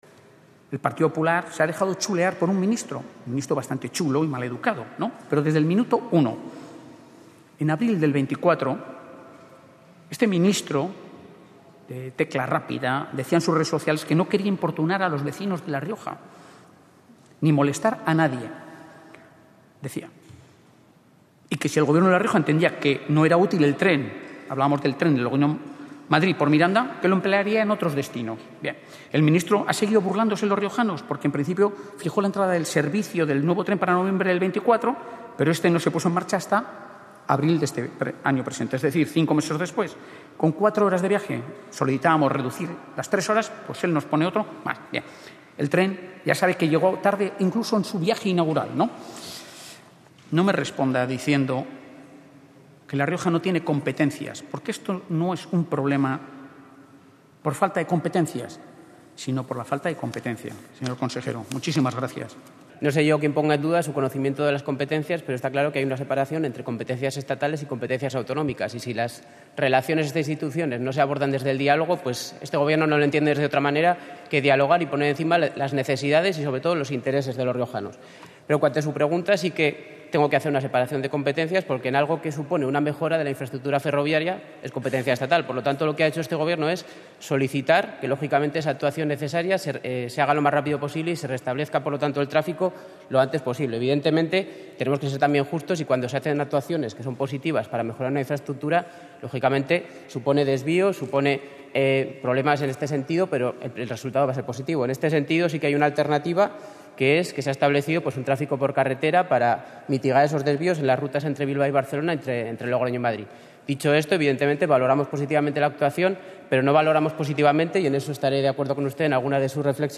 Es la respuesta del responsable de infraestructuras del Gobierno de La Rioja a la pregunta de VOX en el Parlamento riojano.